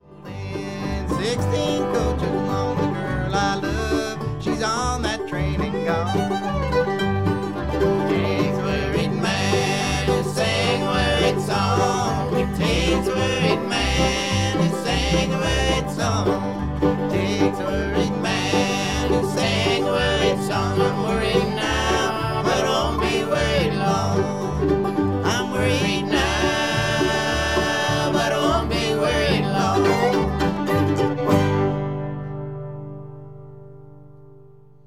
Band version (key of G)